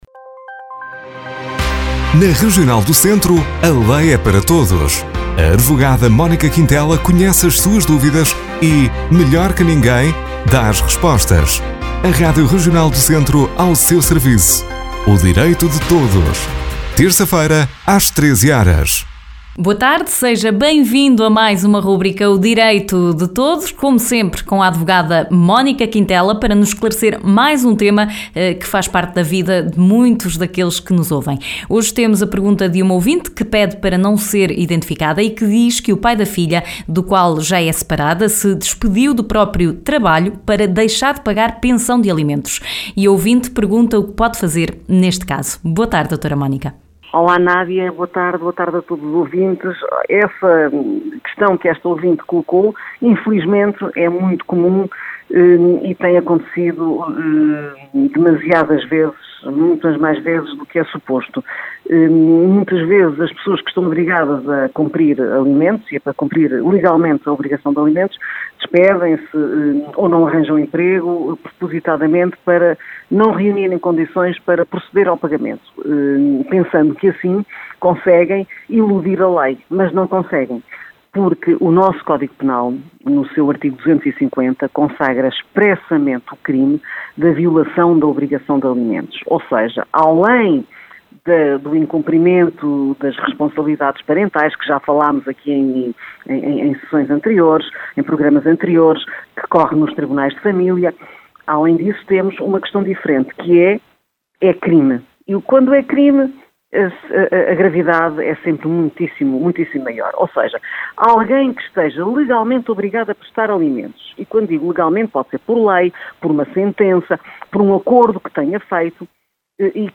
Na rúbrica de hoje a advogada Mónica Quintela responde a uma ouvinte sobre a violação de dever de pagamento da pensão de alimentos.